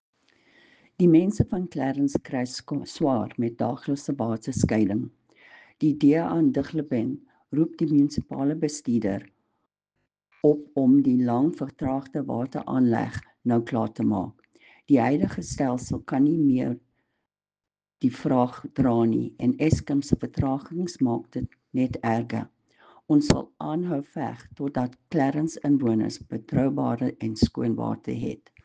Afrikaans soundbites by Cllr Irene Rügheimer and